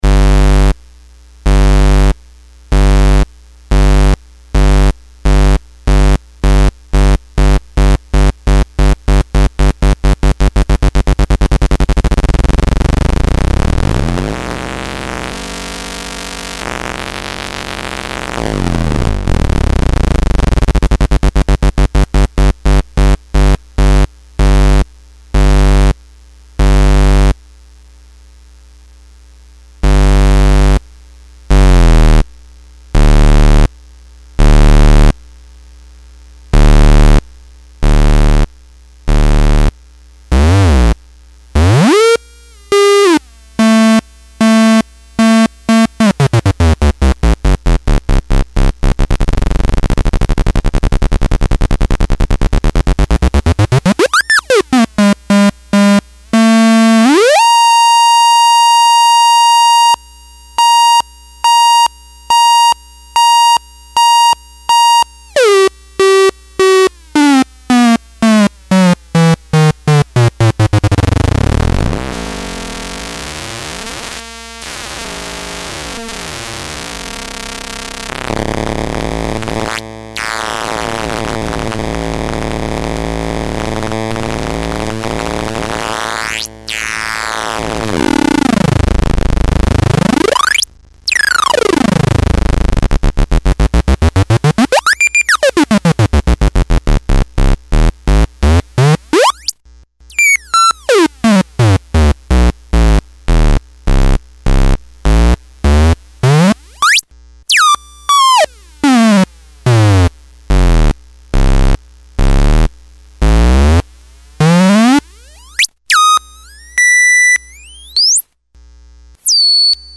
this is an opto-isolated switch that is controlled by a wide range variable speed voltage controllable squarewave clock / lfo. i designed it be used as a simple on/off switch to work with bends but it can also be used alone as a stand alone stutter box, a vibrato, and just about anything else you can think of. being as how it is optically controlled it also smoothes out the signal a bit so there is not any harsh clicking or sharp edges to deal with at all. the CV input has a simple basic linear response that will work up to the supply voltage and should not be exceeded. there is also a little bend switch that i've added that will slightly mangle the source sound and use that to fill in the gap the switch creates. its pretty subtle depending on the source sound but its pretty wild with others turning the gap into a fluctuating synth bass version of the source, or as with the sample below it will act as a decay, its the sort of thing you can leave out and not miss depending on your application. you will also notice that at higher speeds the clatter can get close to sounding like a ring mod or even a wave shaper. using different voltage power supplies will increase or decrease the range of operation respectively. so please take that into account. 9v recommended.
SAMPLE ::: chopping up a simple 40106 squarewave oscillator similar to a single section of the parade while i also vary the pitch of it